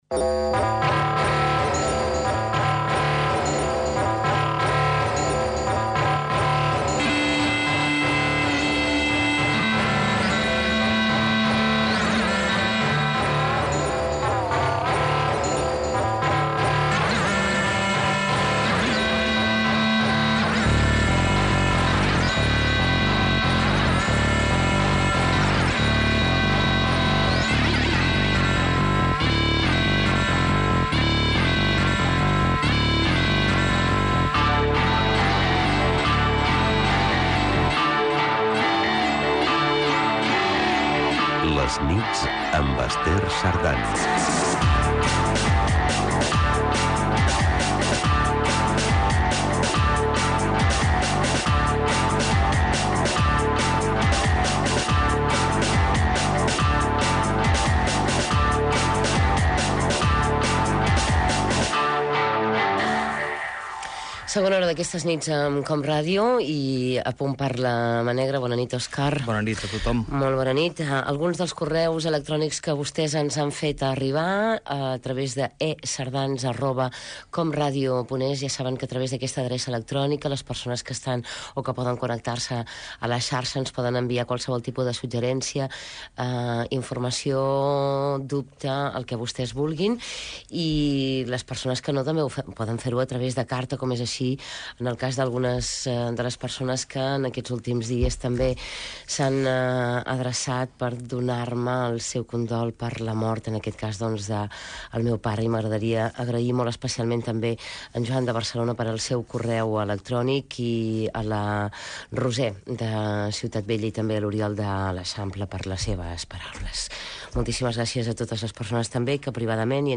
COM Ràdio Emissora COM Ràdio Barcelona
Indicatiu del programa
Gènere radiofònic Entreteniment